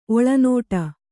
♪ oḷanōṭa